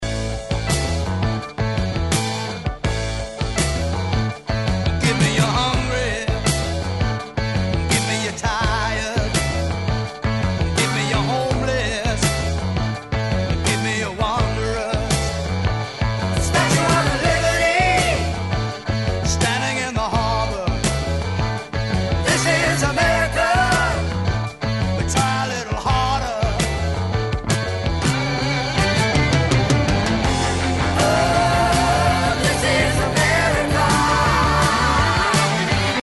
at Trident Studios, London